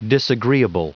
Prononciation du mot disagreeable en anglais (fichier audio)
Prononciation du mot : disagreeable